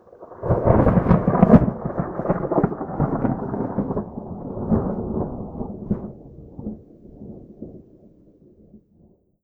tenkoku_thunder_medium06.wav